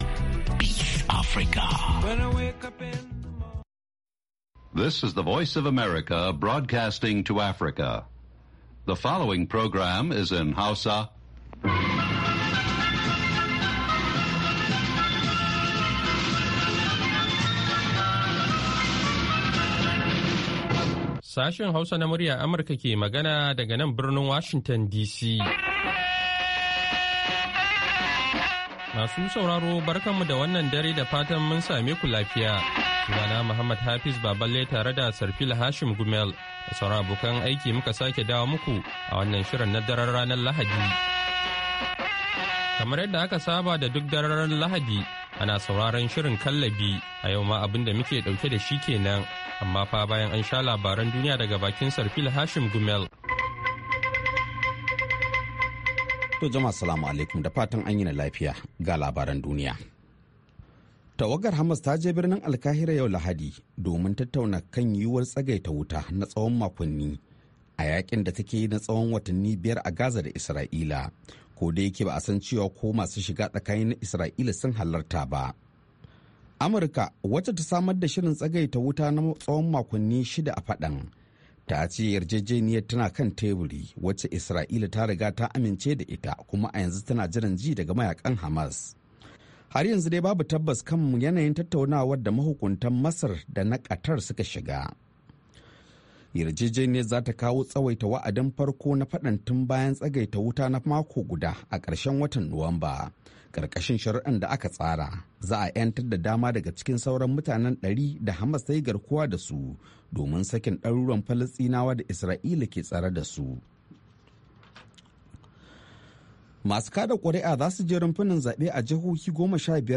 Kallabi wani sabon shiri ne kacokan kan mata, daga mata, a bakin mata, wanda Sashen Hausa na Muryar Amurka ya kirkiro don maida hankali ga baki daya akan harakokin mata, musamman a kasashenmu na Afrika. Shirin na duba rawar mata da kalubalensu ne a fannoni daban-daban na rayuwa.